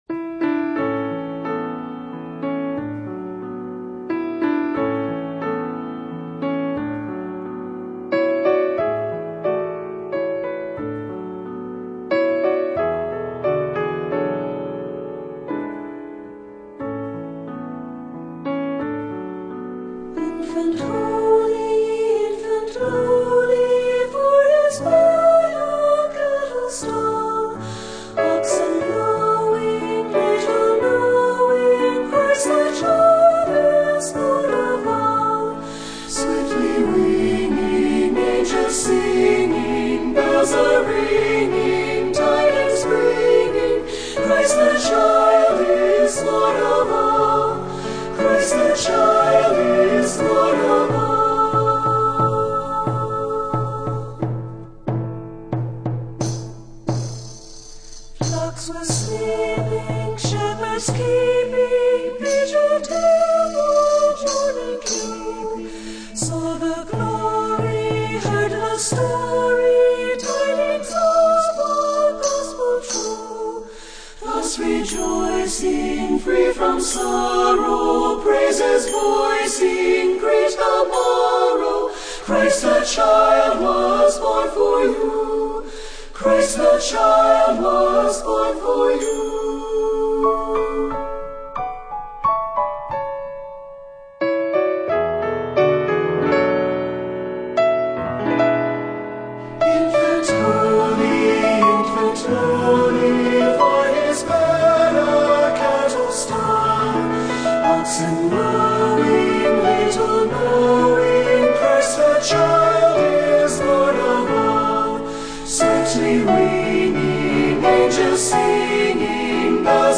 Voicing: SAT